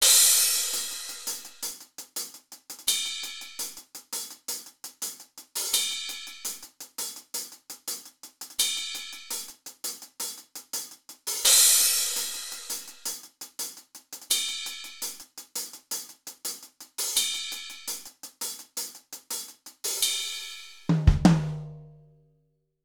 HiHats.wav